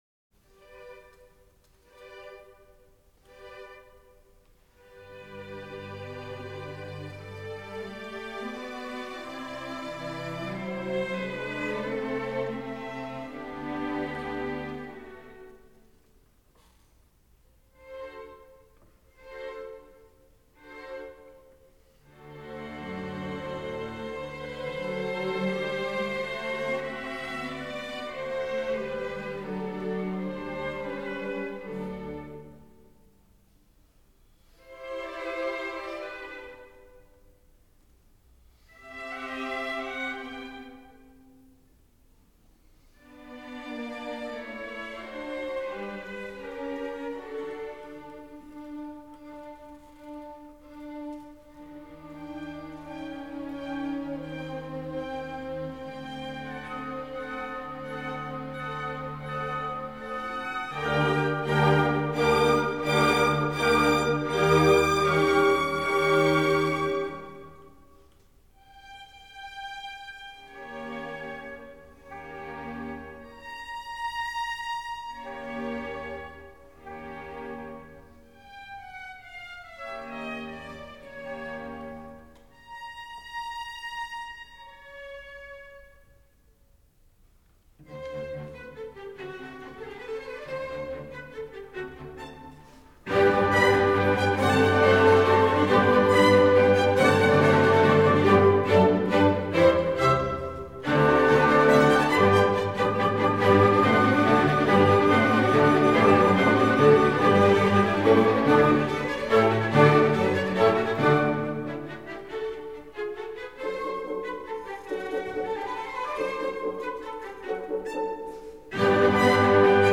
Joseph Haydn: Adagio-Allegro spiritoso.
Société d’Orchestre de Bienne.